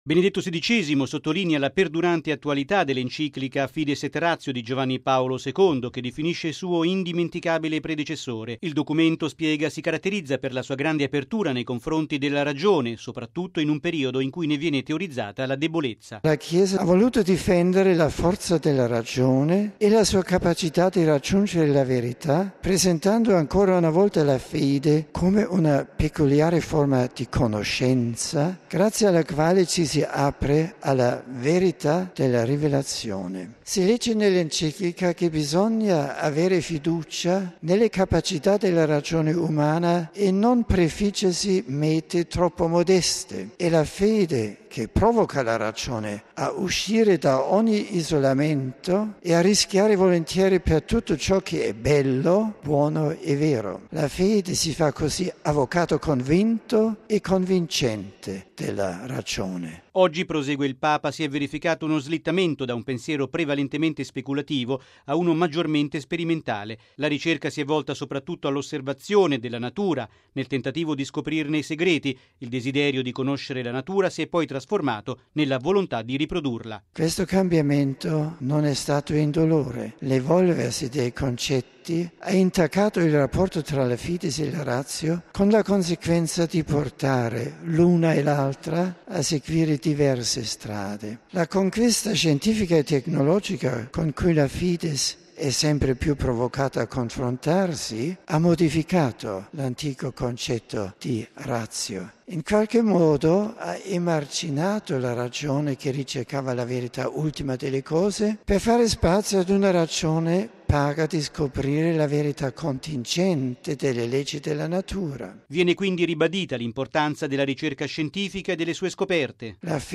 ◊   La Chiesa difende la forza della ragione e la sua alleanza con la fede contro il duplice rischio di una ragione debole, che si sente incapace di trovare la verità, e una ragione arrogante che vorrebbe sostituirsi a Dio. E’ quanto ha detto in sintesi Benedetto XVI nel suo discorso ai partecipanti al Congresso Internazionale promosso dalla Pontificia Università Lateranense, nel X Anniversario dell'Enciclica Fides et Ratio di Papa Wojtyla.